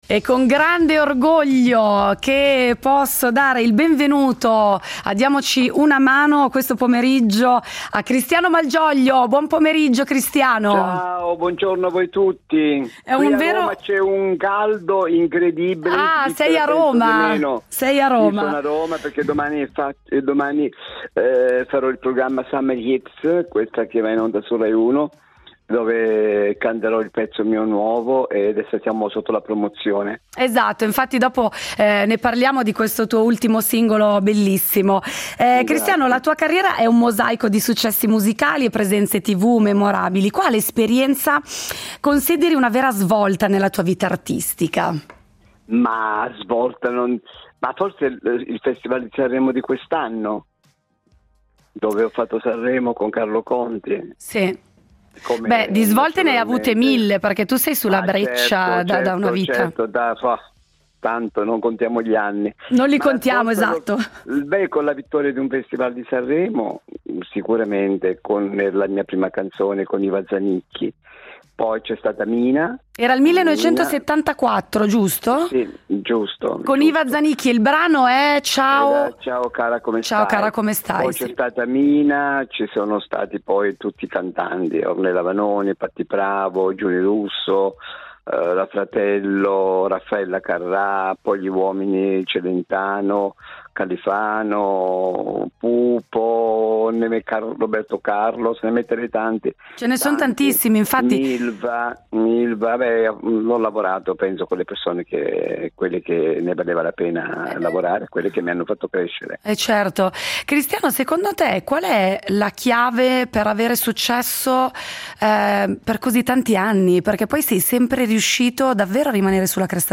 Incontro con Cristiano Malgioglio, cantautore, paroliere e personaggio televisivo